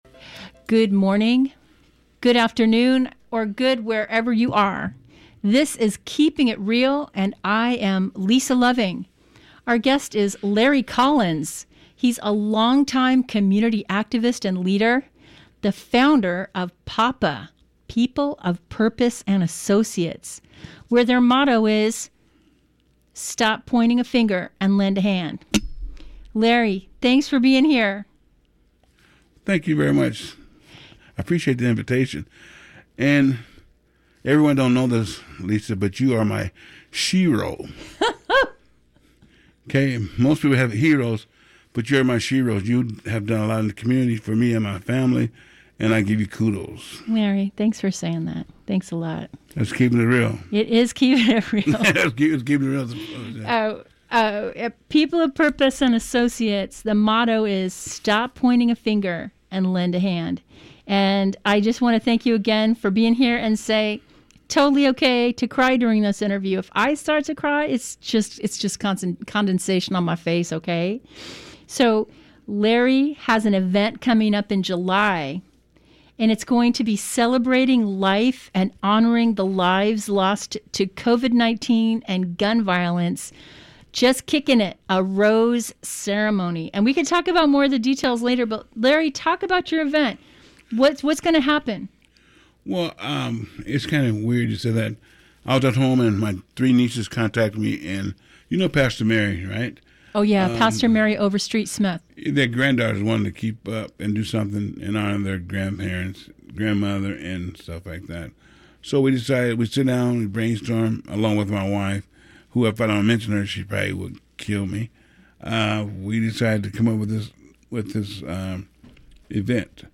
A rerun of May's interview with one of the most beloved community organizers in PDX